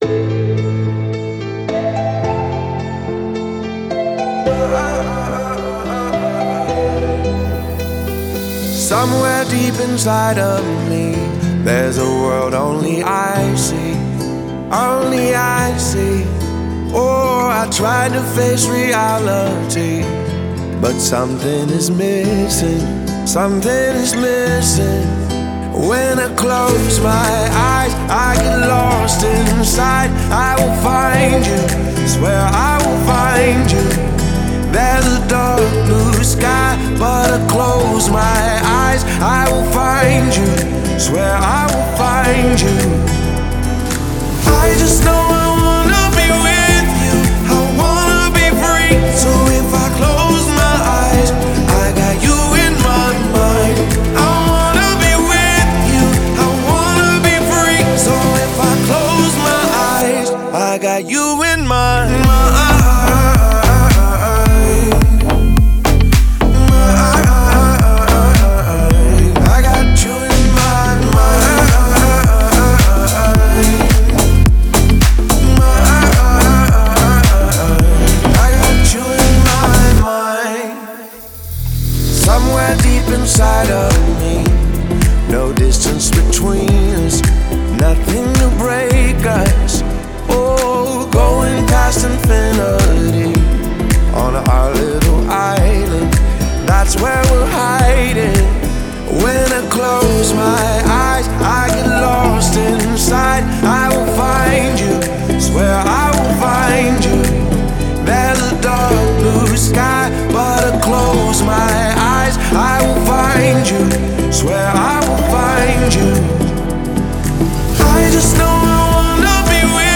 это захватывающая композиция в жанре EDM с элементами попа
мощные электронные биты